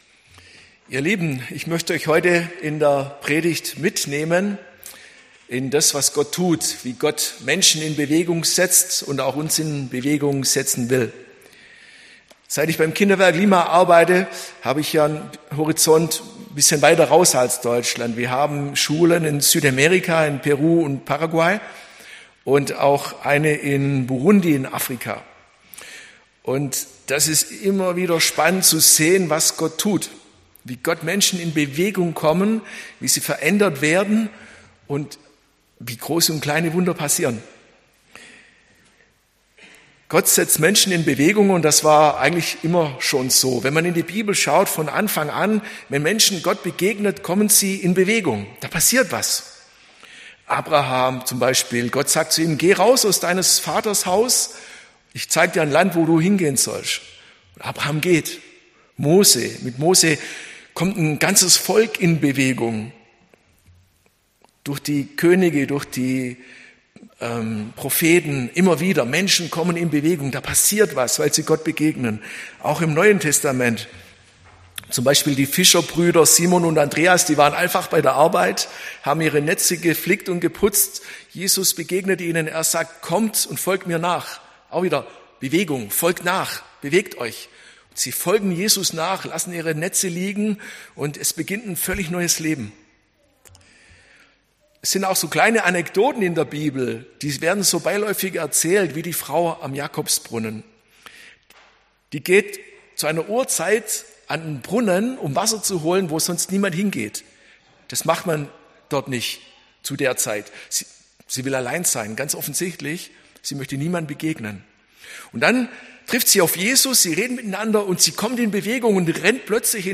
Die Predigten aus den Gottesdiensten unserer Gemeinde finden sie zum Nachlesen und Nachhören in diesem Podcast. Wir wünschen Ihnen ansprechende Gedanken, Ermutigung, aber auch Herausforderung im Glauben.